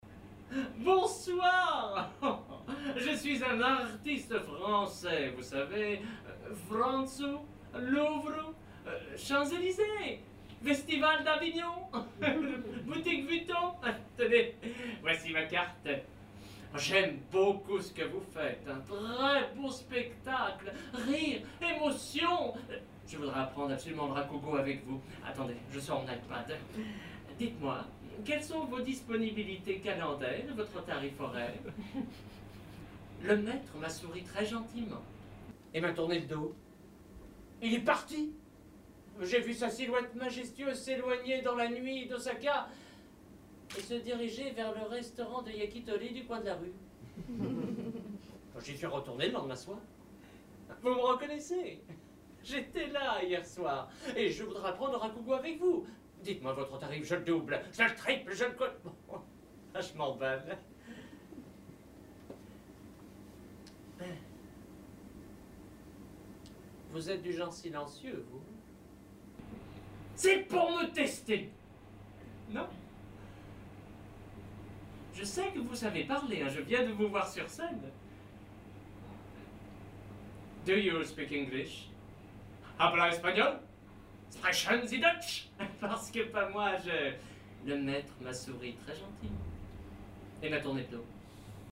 [オーディオブック]
それぞれ枕をはさみながらフランス語で演じています。